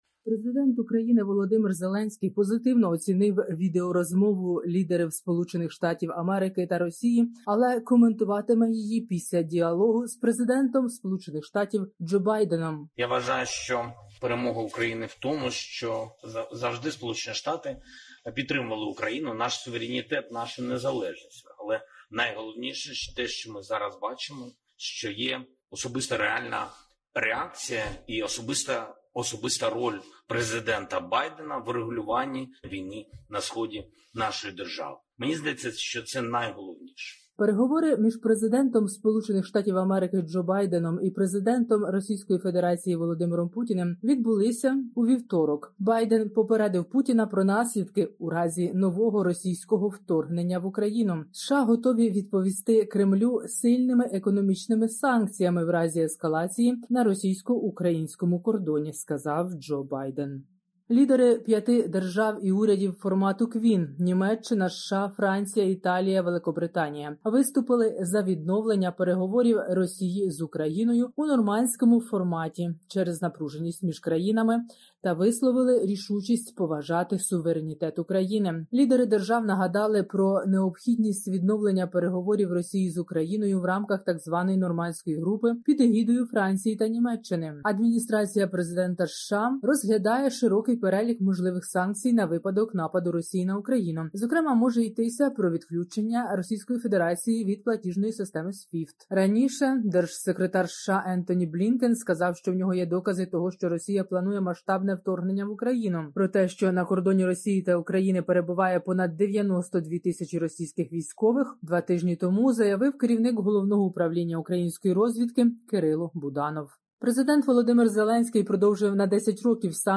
Добірка новин